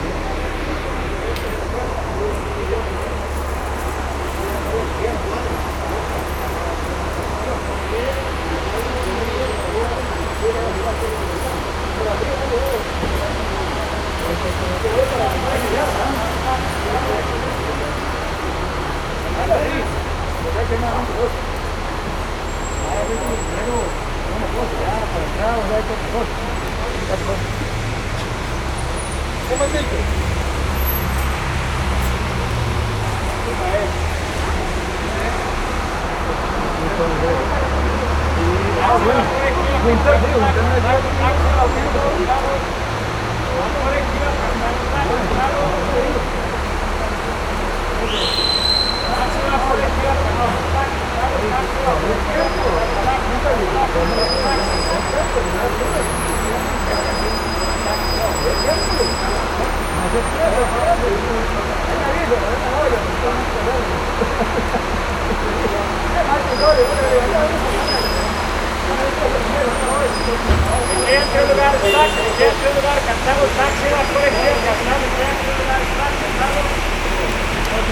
Paisaje sonoro para de Cartago en San Jose SAN JOSÉ